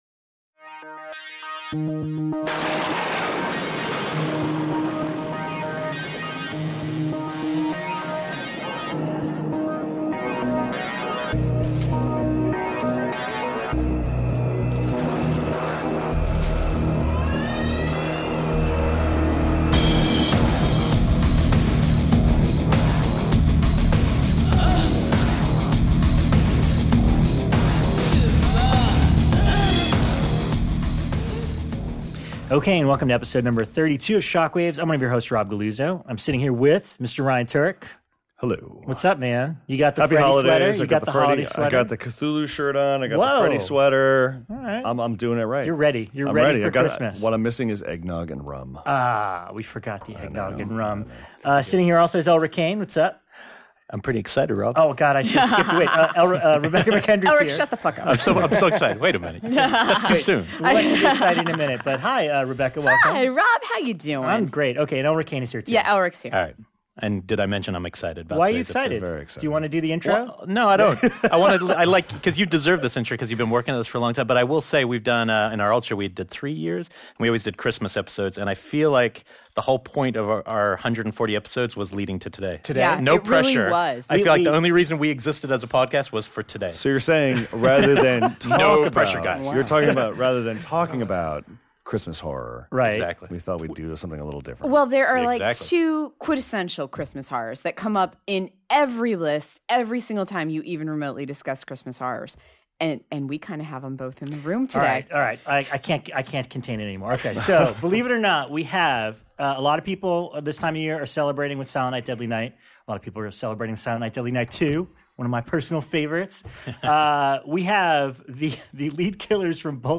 Kick back, grab some spiked egg nog, and listen as we get the full story on how both ended up playing a killer Santa in their respective films, how the controversies after the fact affected them both personally and professionally, and how they discovered the cult fanbase for these movies all these years later. Both are very humble about their cinematic counterparts, and very candid and up front in this in depth interview.